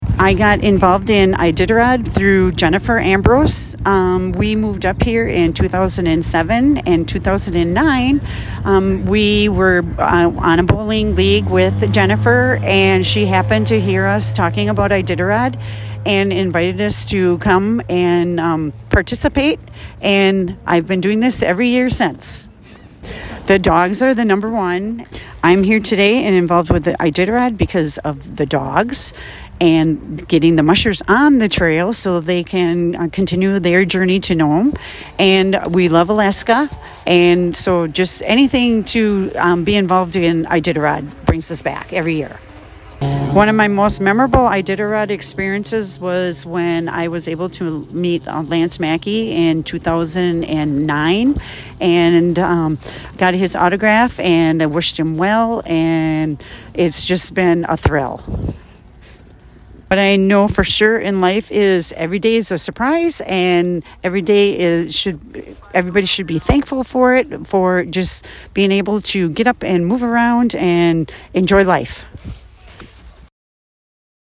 Temperature: 21F/Outdoors